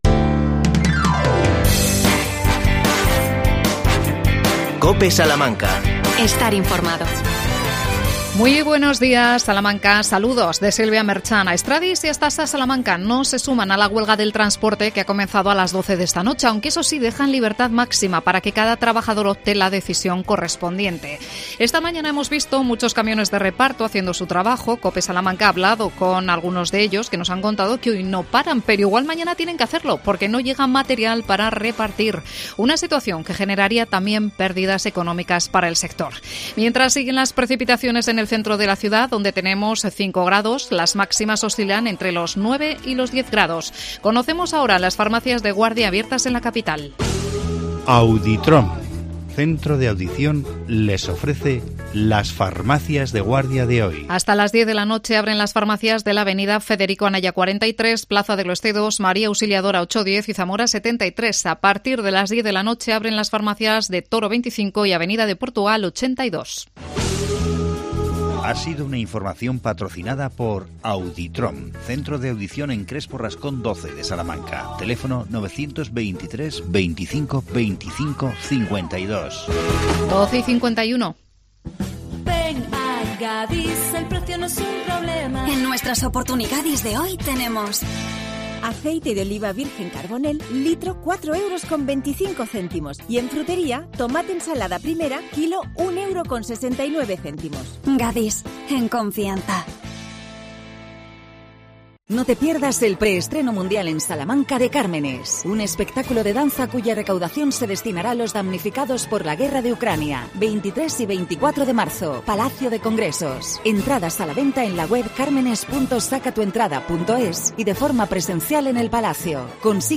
AUDIO: Los vecinos de Sancti Spiritus protestan por el cierre de la oficina bancaria de Unicaja. Entrevistamos a uno de ellos